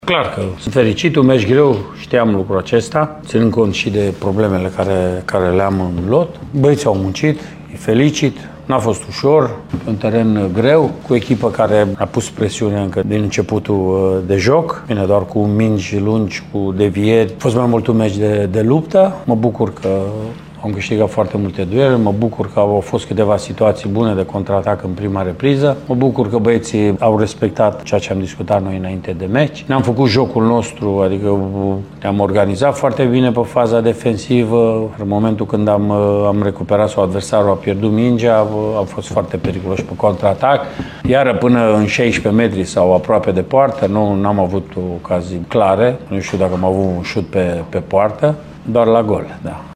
Antrenorul echipei de pe Mureș, Mircea Rednic, a tras concluziile jocului: